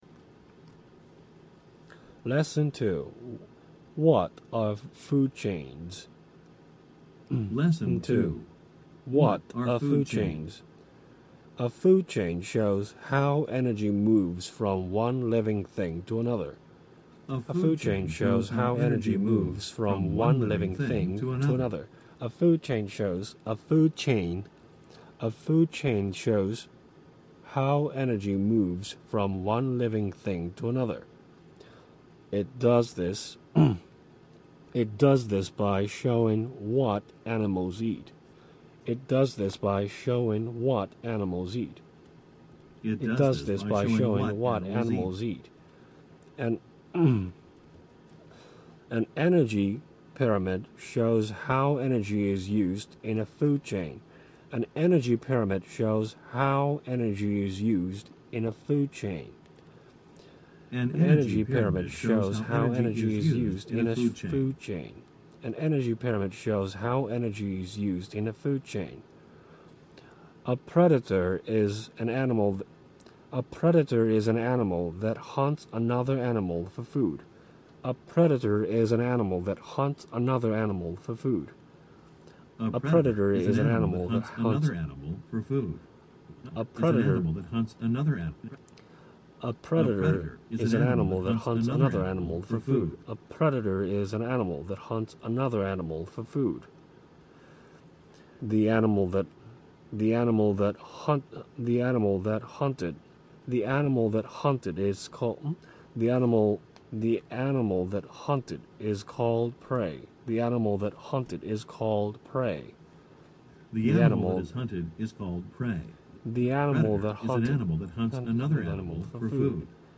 音読の様子をお伝えしたいと思う。
「読み上げCD」と一緒に音読して、
スピードとイントネーションを調整する。